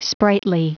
Prononciation du mot sprightly en anglais (fichier audio)
Prononciation du mot : sprightly